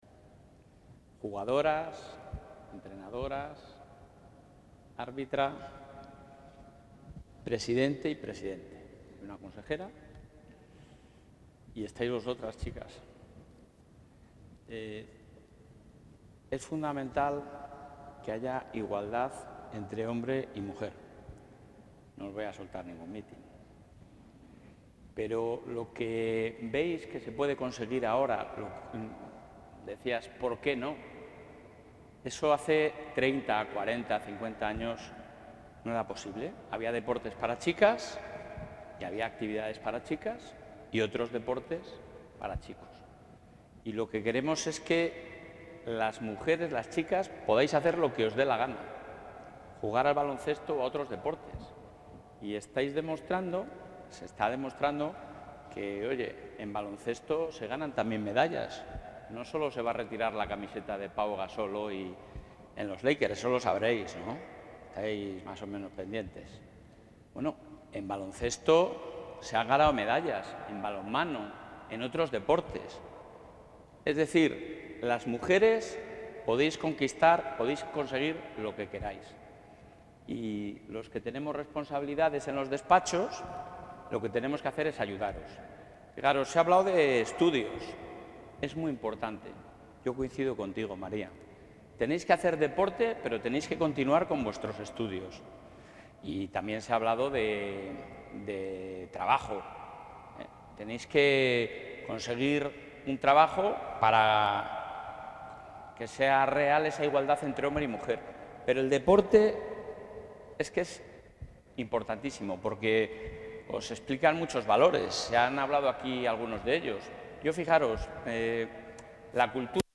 Intervención del presidente.
El presidente de la Junta de Castilla y León ha participado en un encuentro con jugadoras del Programa Regional de Detección (PRD) de la Federación de Baloncesto de Castilla y León, con motivo de la celebración del Día Internacional de la Mujer